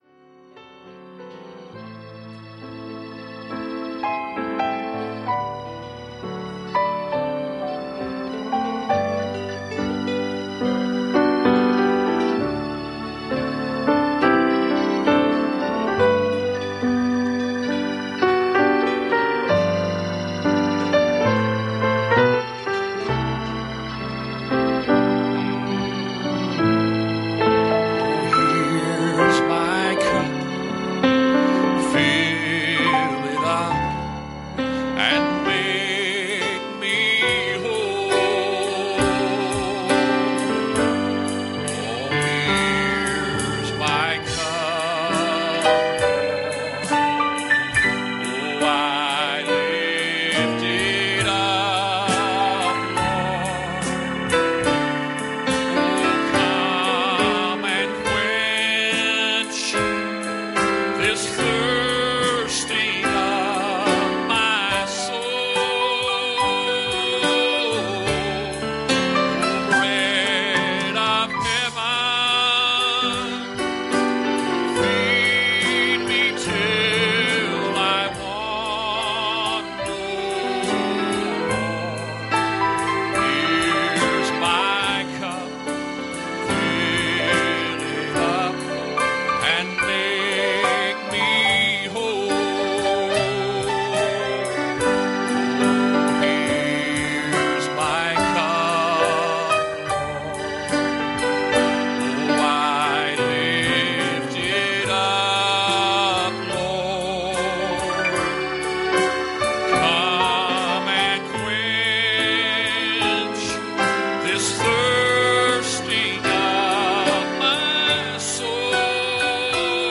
Passage: Exodus 14:19-20 Service Type: Sunday Morning